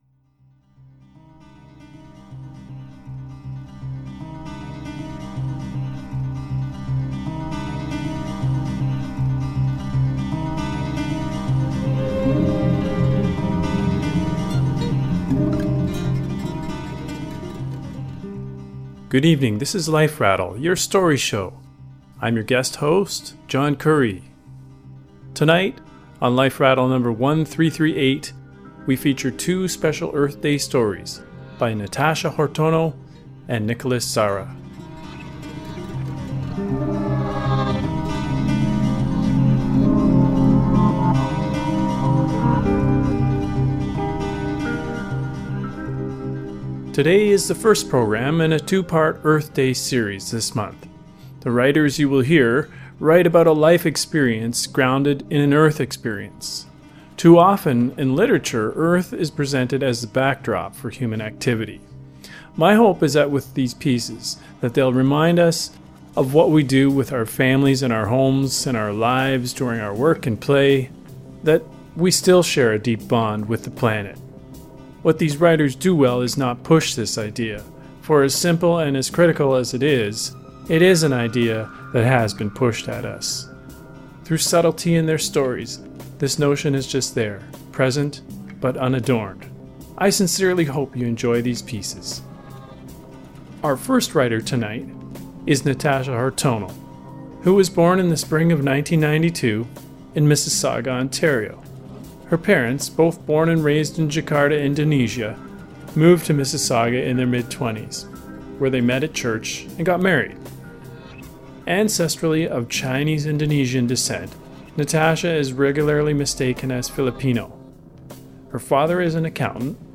"Rain Dance" is a travelogue.